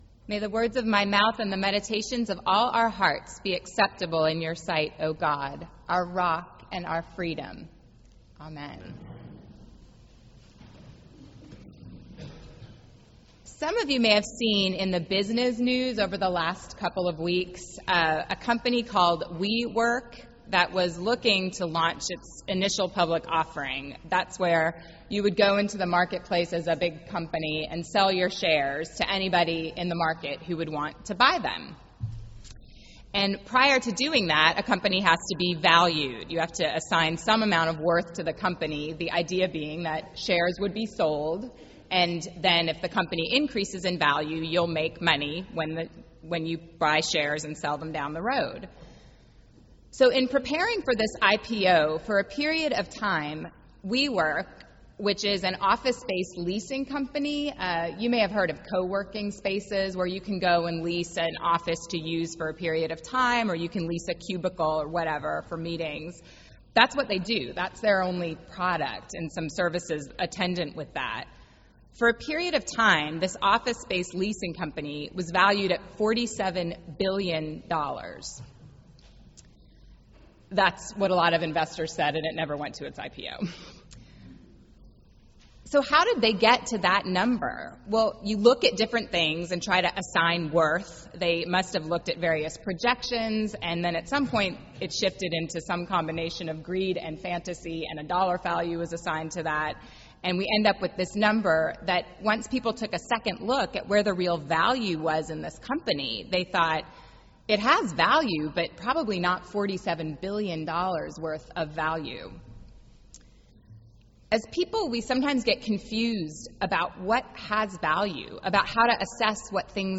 Sermon - The 16th Sunday after Pentecost